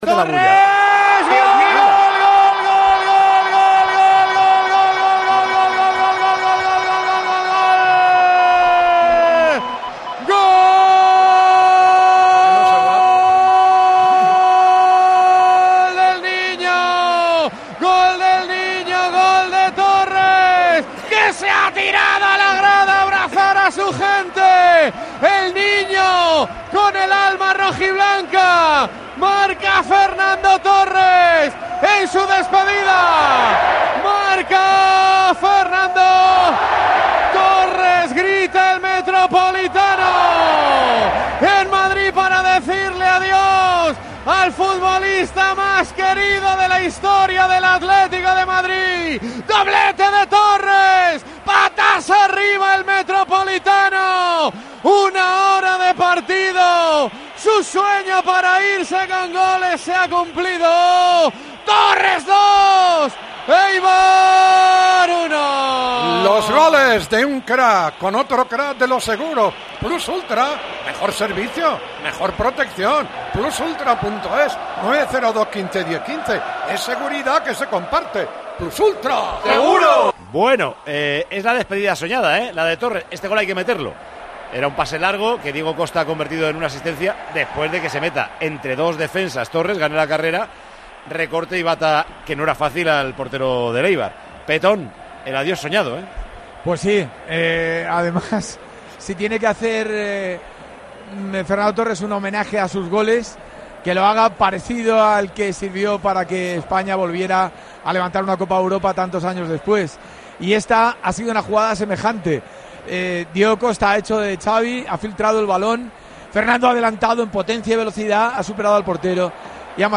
Así se han cantado los goles del año en 'Tiempo de Juego'